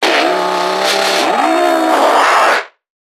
NPC_Creatures_Vocalisations_Infected [65].wav